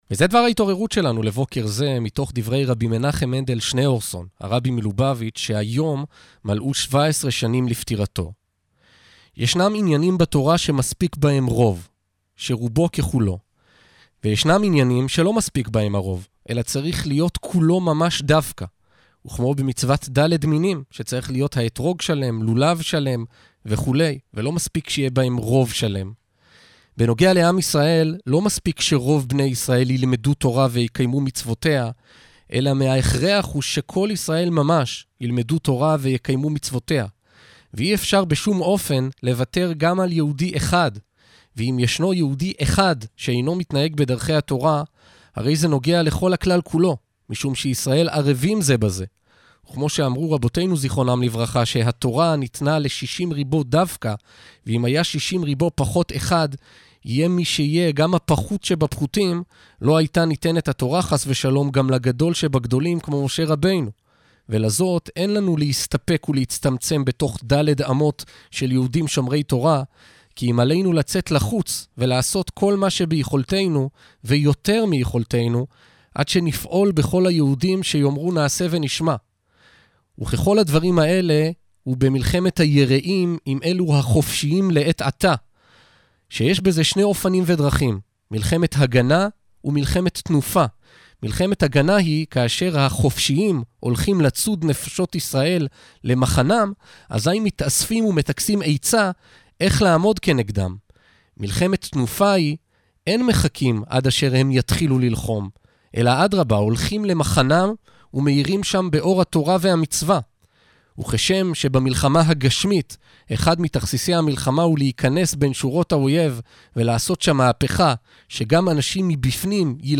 הראיון הנדיר של הרבי הוקרא בתוכנית הבוקר הפופולרית ● האזינו